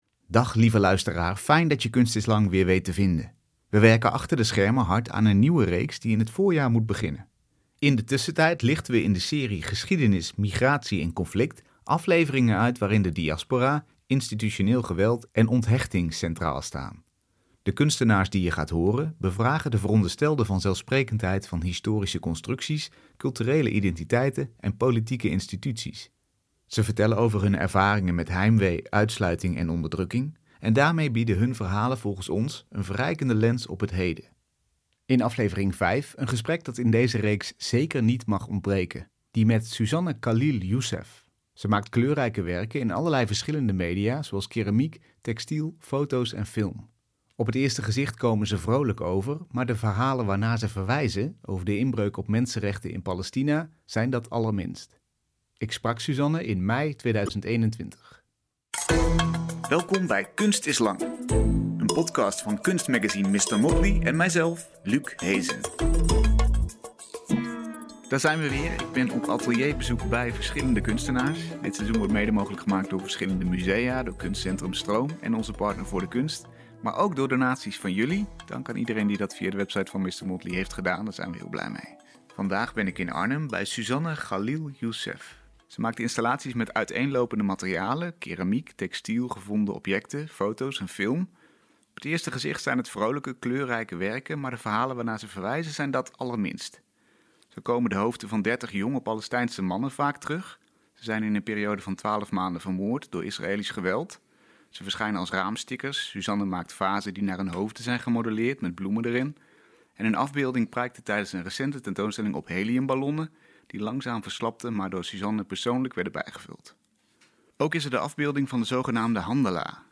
In deze aflevering hoor je het gesprek